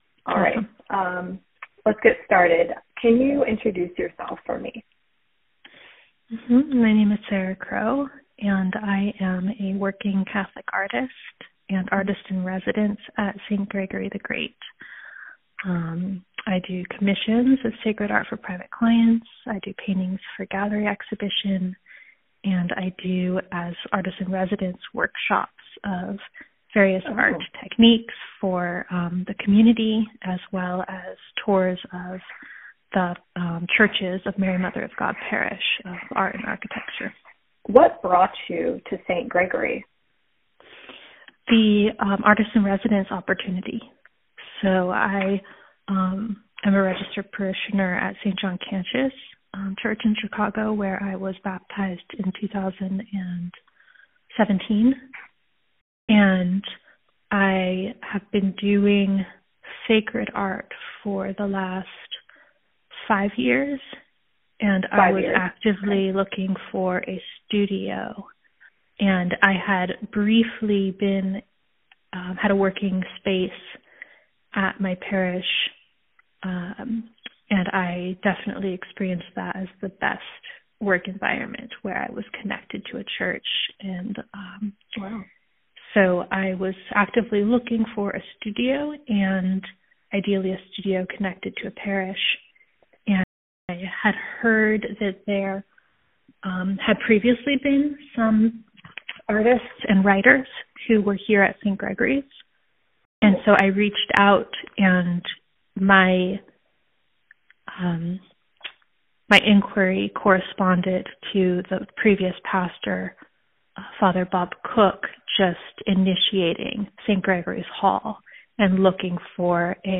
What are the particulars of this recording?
This interview was conducted over phone and was about twenty minutes in length, transcribing to about five pages.